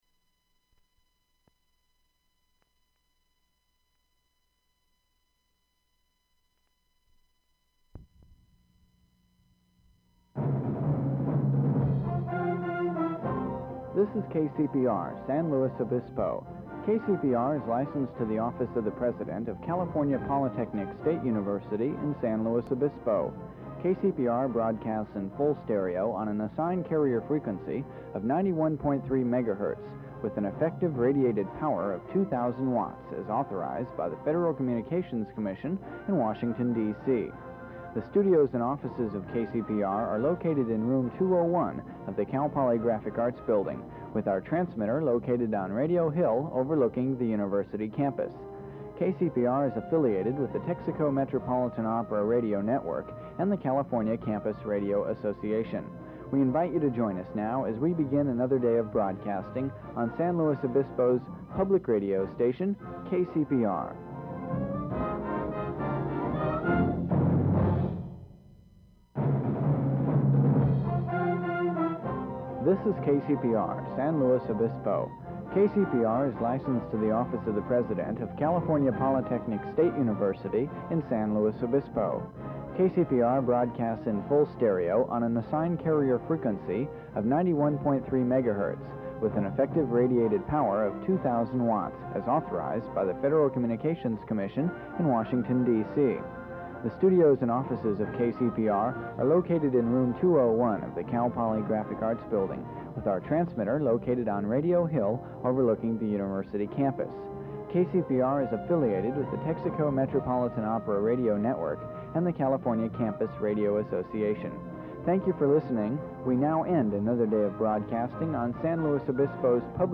KCPR Open Jingles Spots
Form of original Open reel audiotape